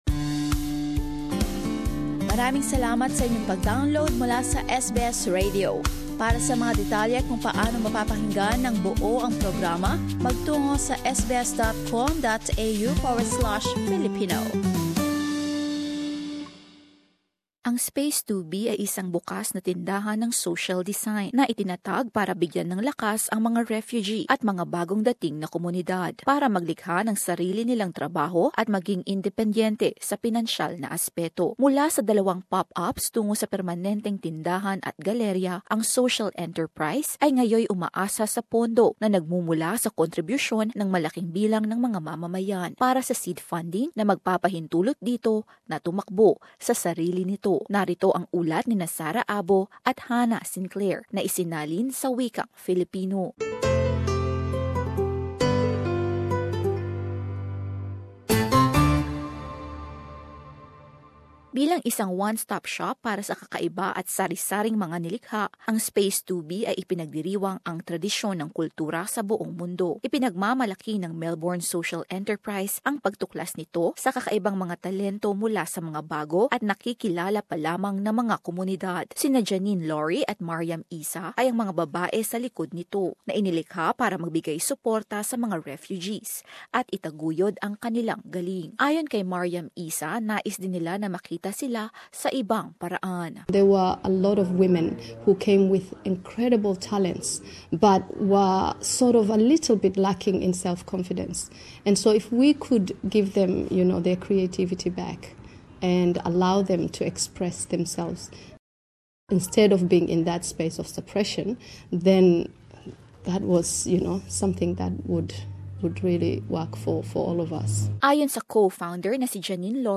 Narito ang ulat